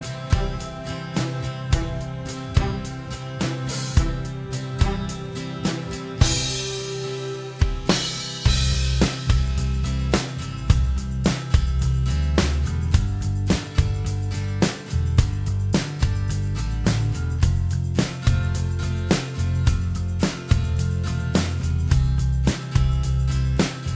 Minus Electric Guitar Pop (1990s) 3:30 Buy £1.50